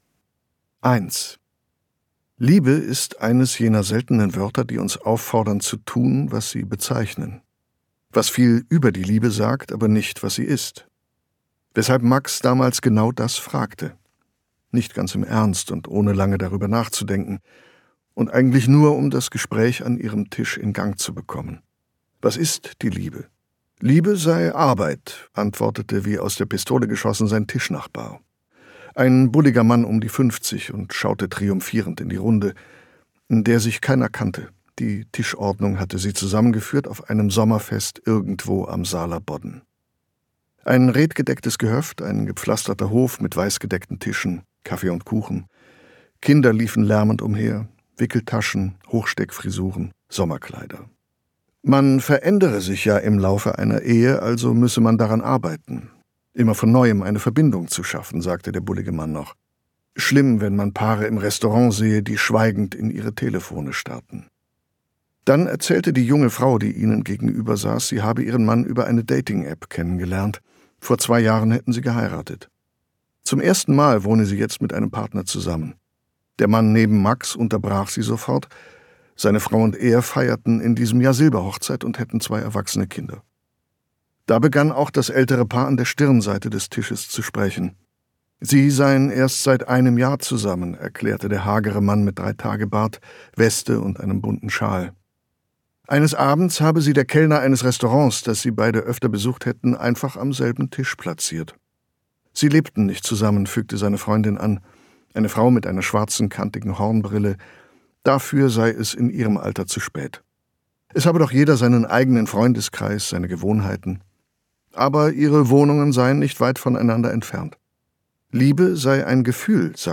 Gekürzt Autorisierte, d.h. von Autor:innen und / oder Verlagen freigegebene, bearbeitete Fassung.
Liebe Gelesen von: Matthias Brandt, Martina Gedeck